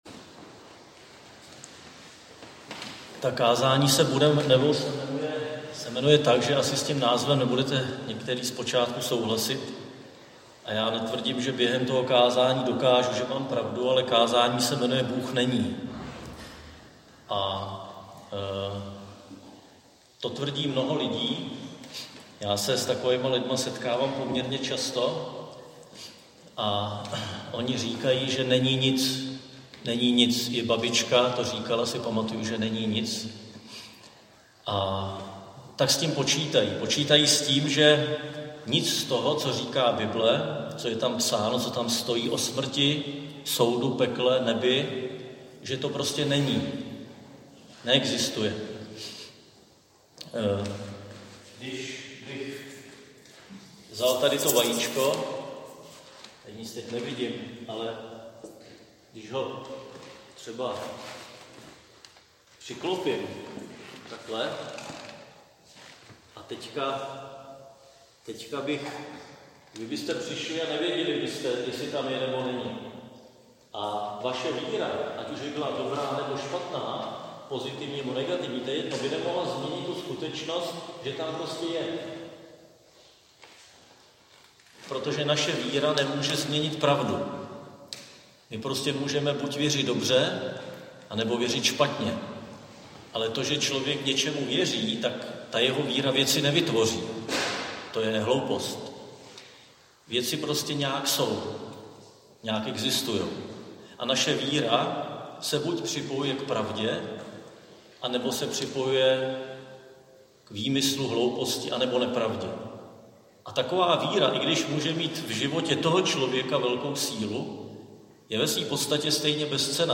Křesťanské společenství Jičín - Kázání 7.6.2020